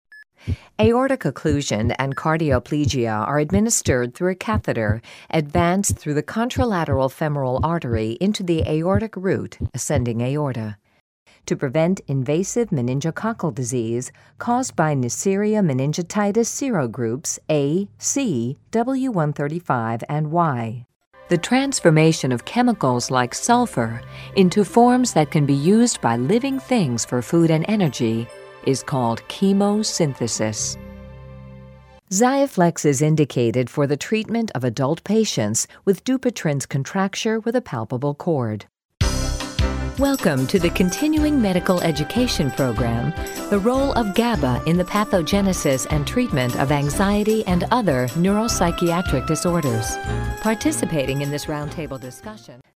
American Female Voice Talent, Narrator, Voice Over commercials, telephone voice, video games, e-learning and medical narrations, characters too
englisch (us)
Sprechprobe: Sonstiges (Muttersprache):